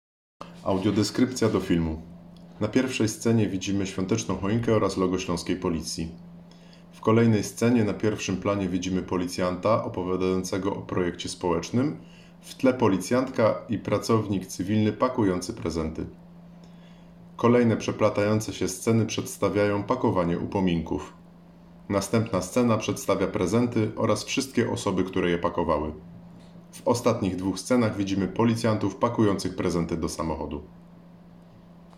Opis nagrania: Audiodeskrypcja do filmu